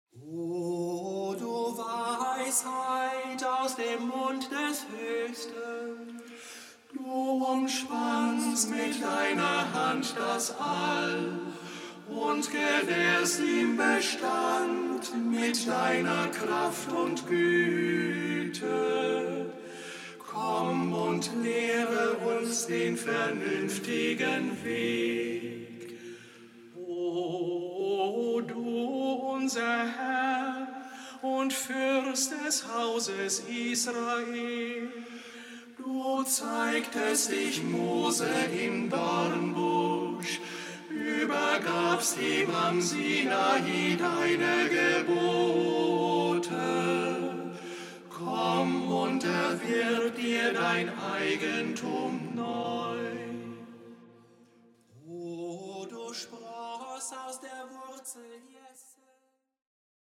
kirchlicher Gesänge und Gebete